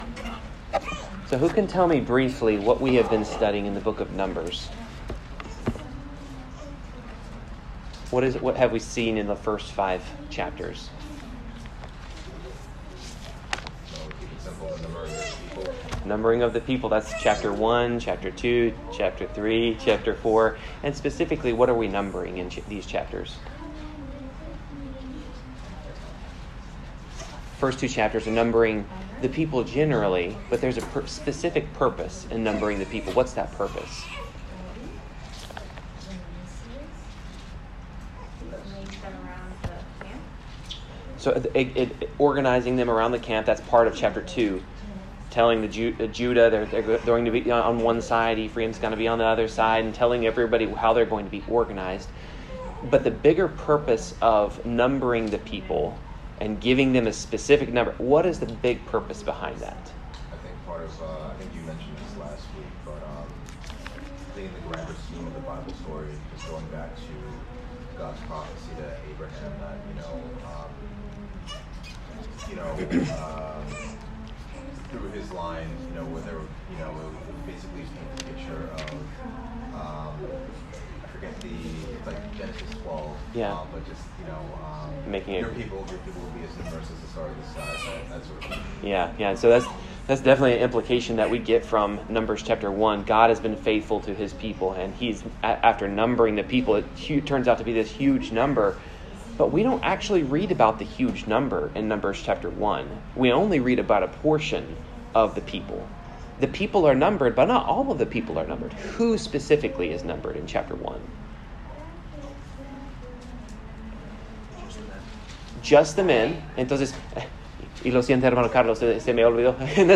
Numbers Service Type: Bible Class The Lord continues to prepare the hearts of his people for entrance into the Promised Land.